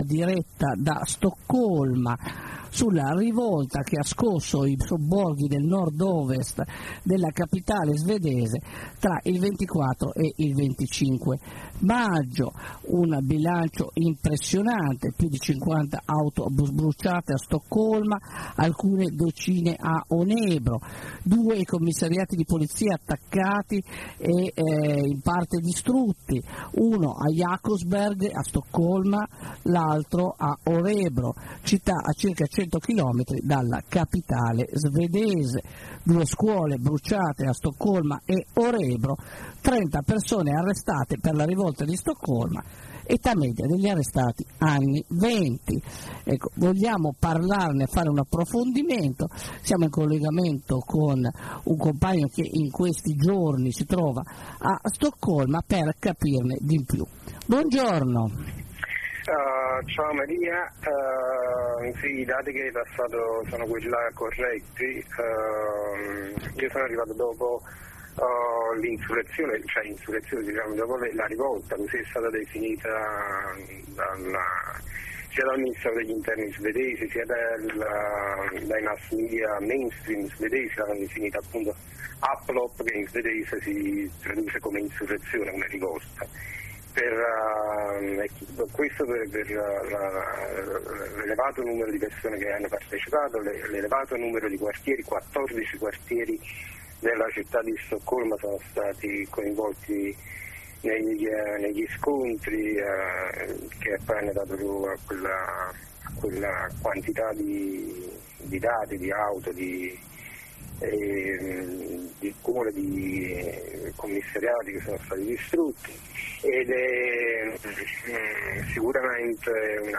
Corrispondenza da Stoccolma.
Anarres ne ha parlato con un compagno, che a vissuto a lungo in Svezia, ed oggi vi è tornato per lavoro.